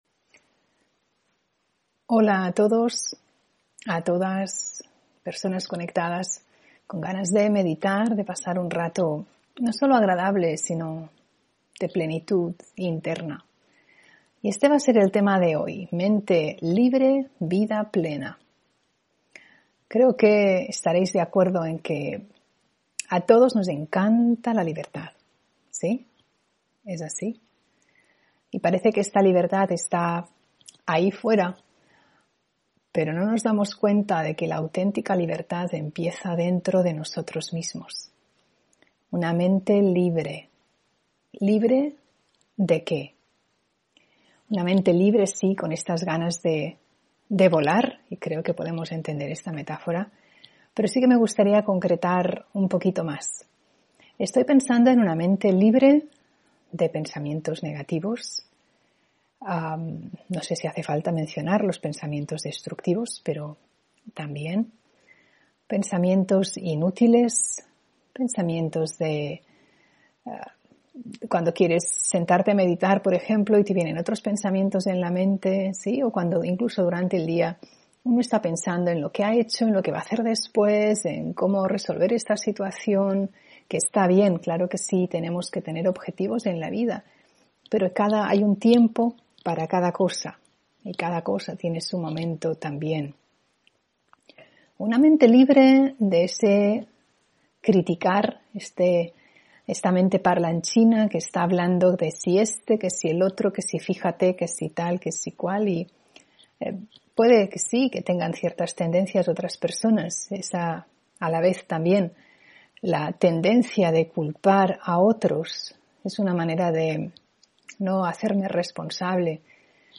Meditación y conferencia: Mente libre. Vida plena (13 Enero 2022)